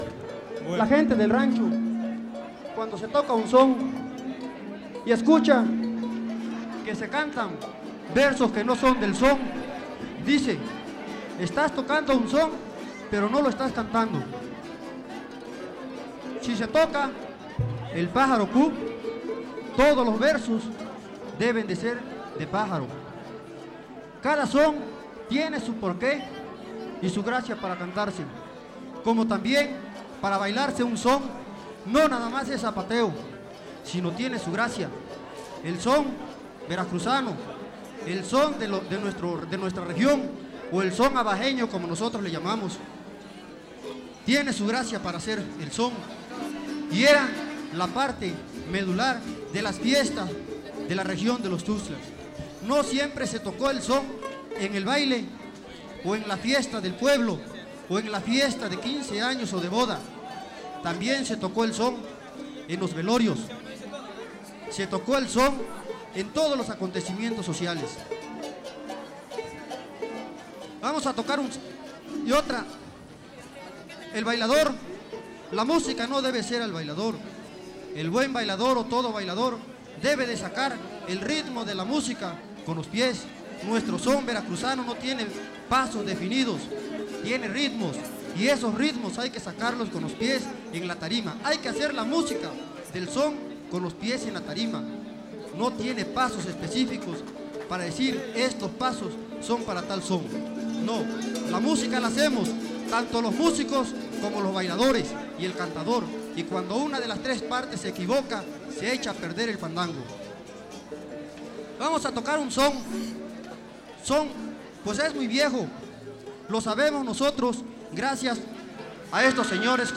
• Cultivadores del son (Grupo musical)
Sexto Encuentro de Etnomusicología. Fandango de clausura